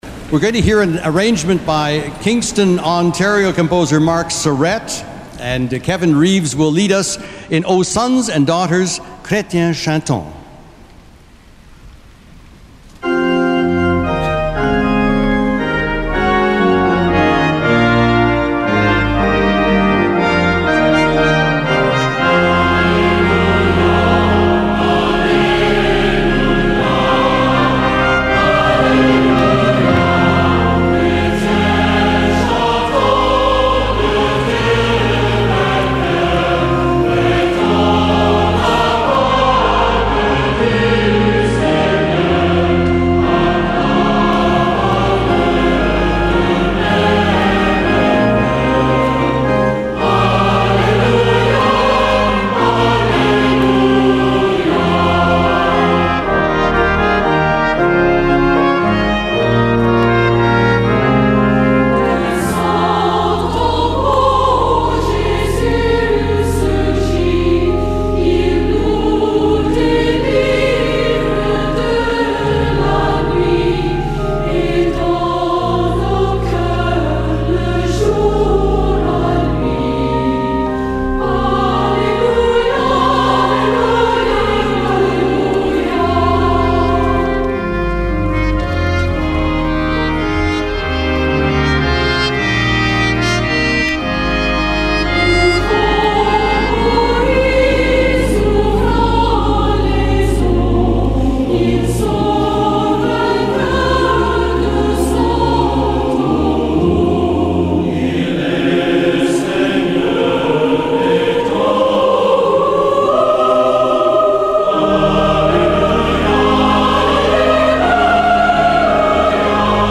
SATB (4 voices mixed) ; Octavo score.